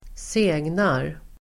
Ladda ner uttalet
Uttal: [²s'e:gnar]